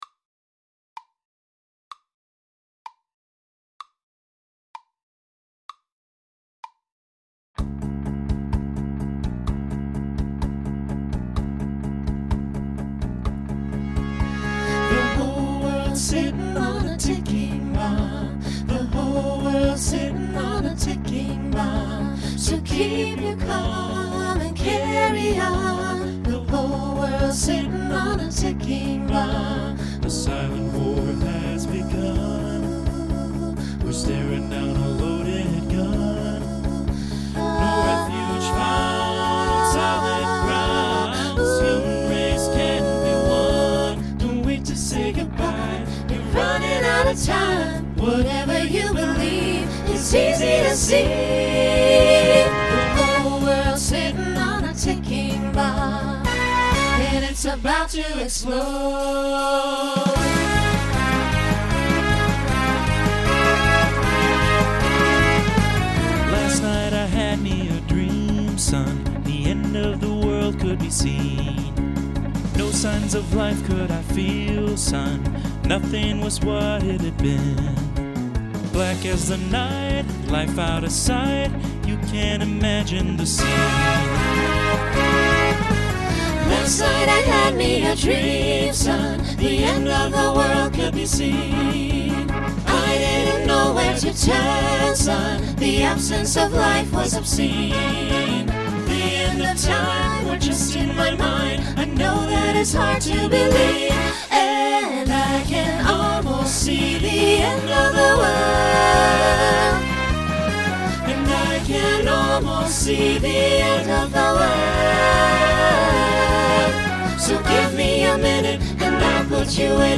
Genre Rock Instrumental combo
Story/Theme Voicing SATB